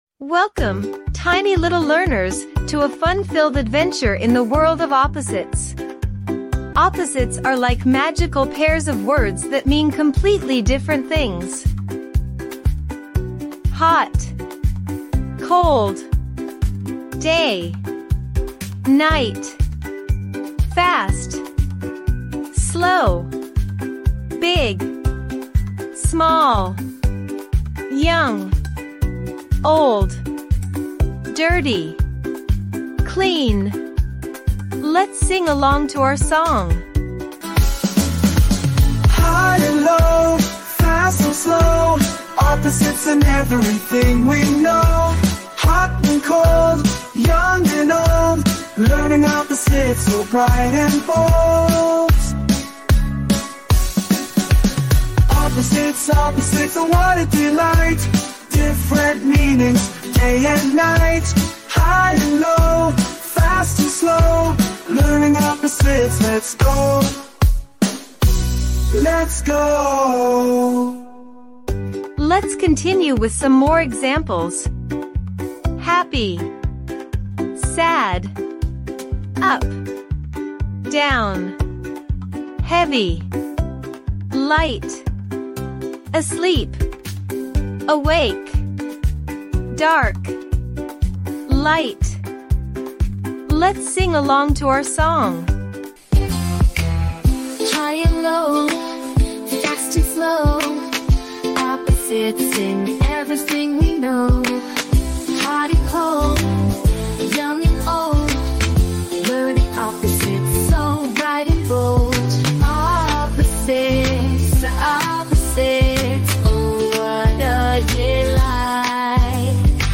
Opposites Song for Kids! 🎶 Join us on an exciting musical journey as we explore the world of antonyms.
Catchy and upbeat melodies: Our lively music will keep your child engaged and entertained while they absorb new vocabulary.